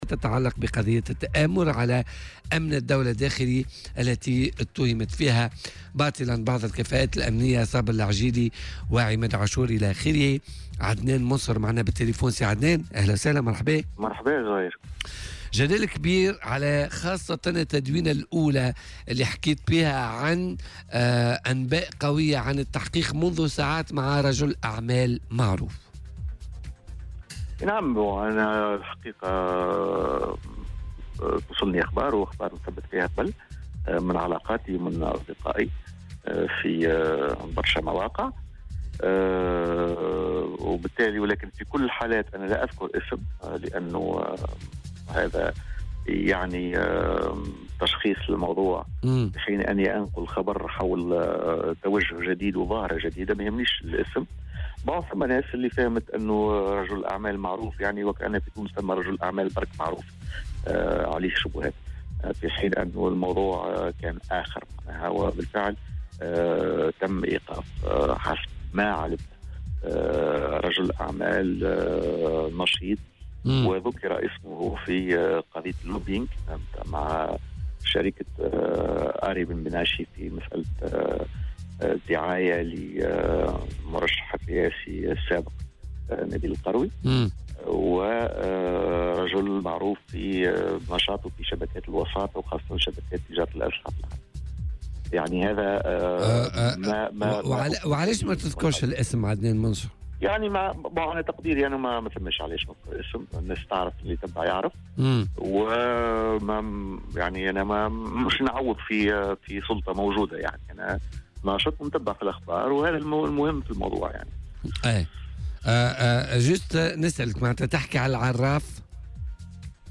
وتابع في مداخلة هاتفي مع "بوليتيكا" على "الجوهرة اف أم" أن رجل الأعمال كان ذُكر اسمه في ملف عقد اللوبيينغ الذي ارتبط بمترشّح سابق للانتخابات الرئاسيّة كما أنه متورط في تجارة الأسلحة.